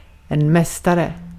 Ääntäminen
US : IPA : /ˈmæs.tɚ/